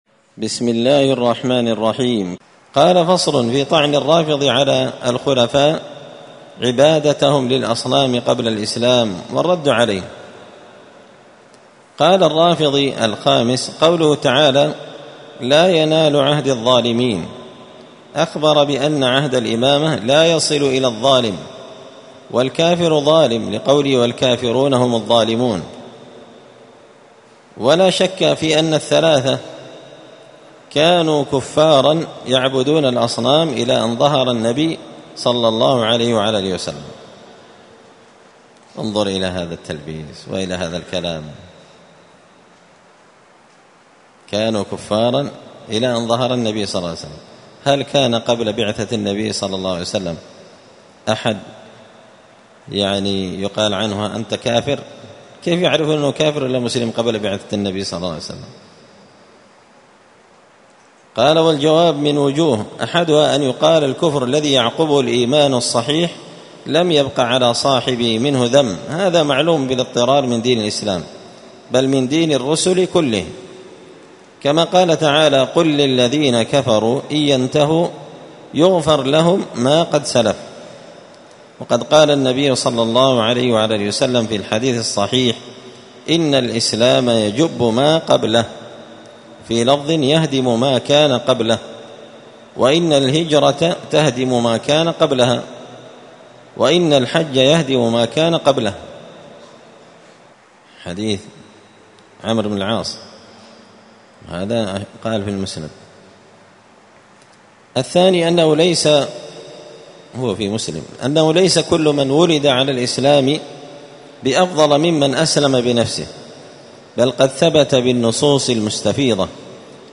الأربعاء 12 ربيع الأول 1445 هــــ | الدروس، دروس الردود، مختصر منهاج السنة النبوية لشيخ الإسلام ابن تيمية | شارك بتعليقك | 10 المشاهدات
مسجد الفرقان قشن_المهرة_اليمن